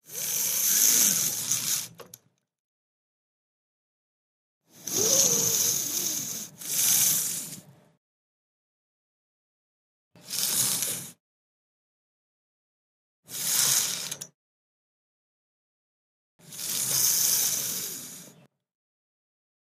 Persian Blind, Persienne, Open, Close x5